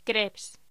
Locución: Crèpes
voz